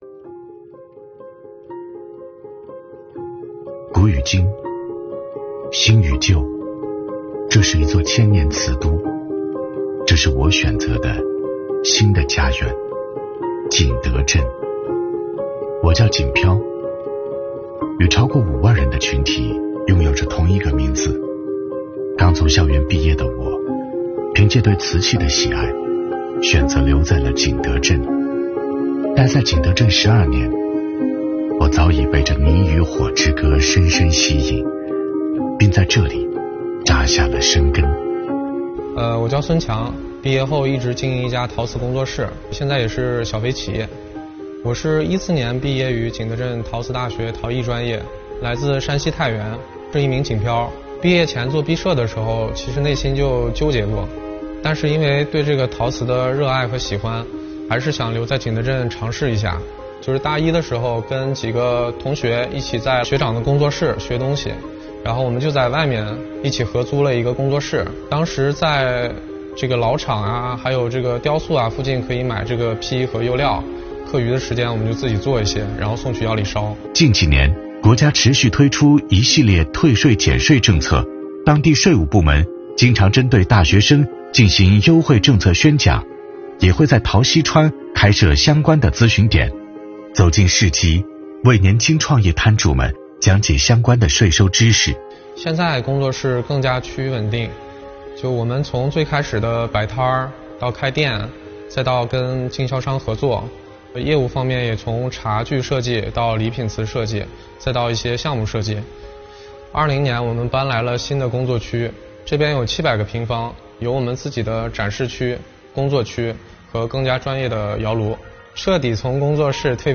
作品采用人物访谈加场景还原，配音旁白加主人公自述的叙事方式，讲述了一个典型景漂年轻人的创业故事，展示了税务部门出台一系列帮扶政策及举措，助力景漂的创业梦。